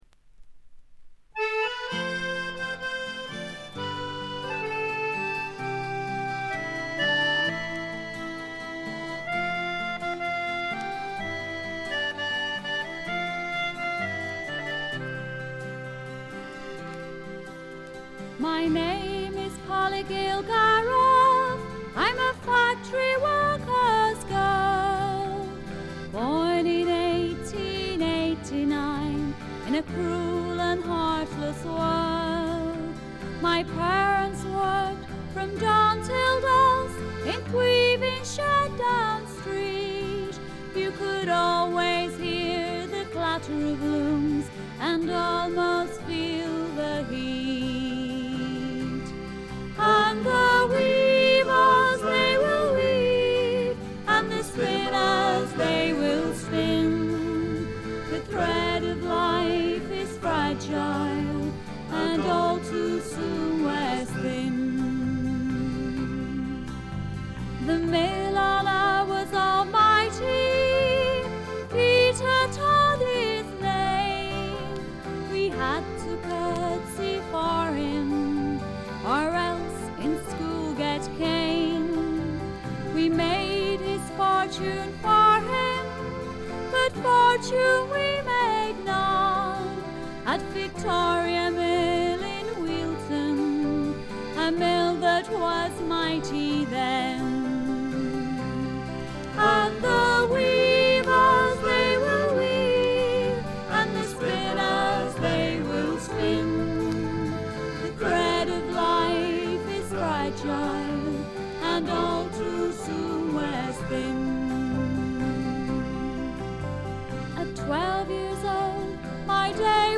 部分試聴ですがチリプチ、プツ音少々。
男女ヴォーカルでいたってフツーのフォークを演っていますが、こういうのもいいもんです。
試聴曲は現品からの取り込み音源です。